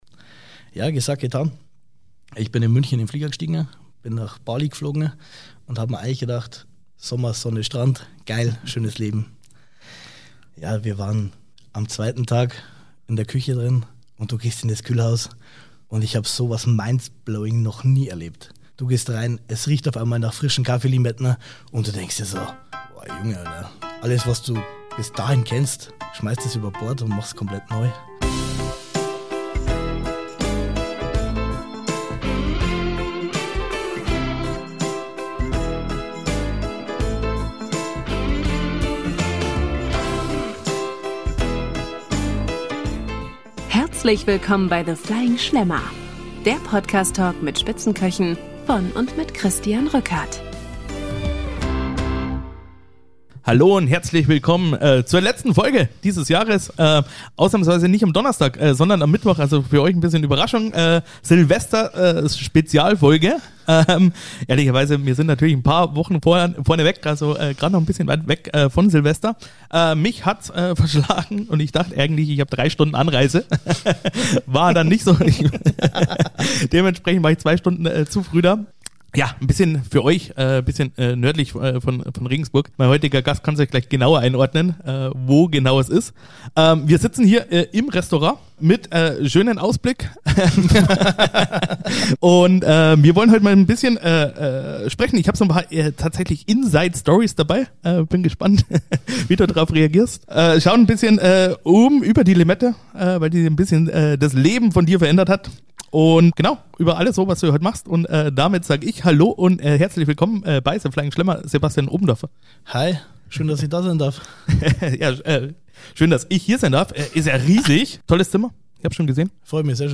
In der Folge wird es zwischendurch ziemlich eng, denn mit zugespielten Themen bringe ich meinen Gast ordentlich unter Druck – inklusive rausgepiepstem Moment und göttlicher Reaktion. Außerdem sprechen wir über Teamarbeit auf Topniveau, Anspannung vor dem Service, Ausgleich zur Küche und das virale Getränk Dirty Soda. Eine laute, ehrliche Silvester-Bonusfolge mit viel Spaßfaktor.